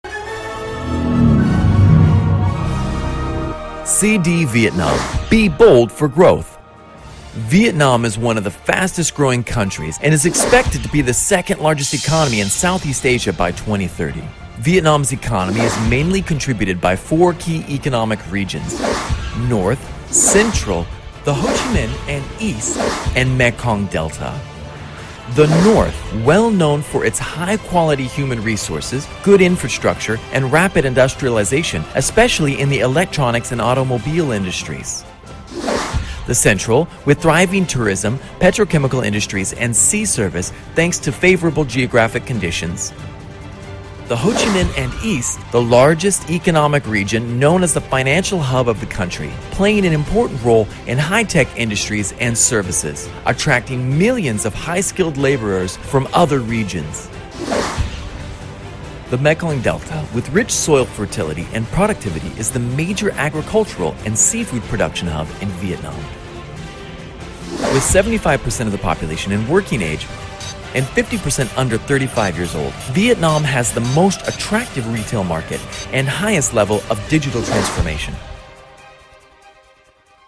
Giọng nam người Mĩ
Video CD Vietnam (style nhiệt huyết, sôi động)